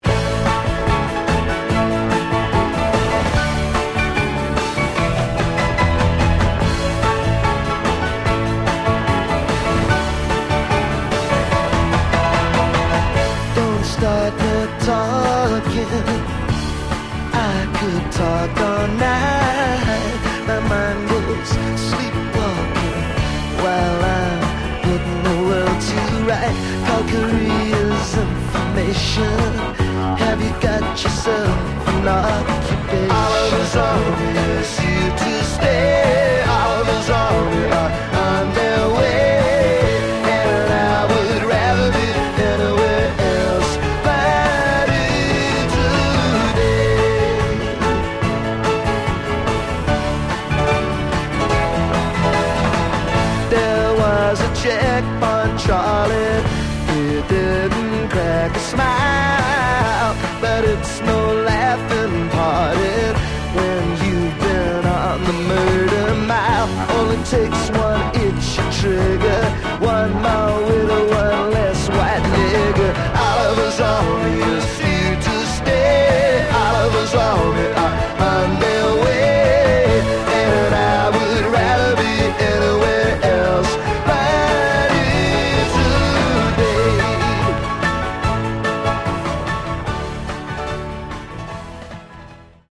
Category: Power Pop